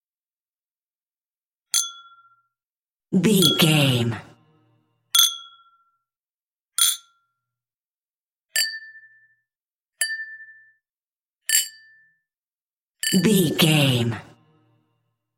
Glass clink 142
Sound Effects
foley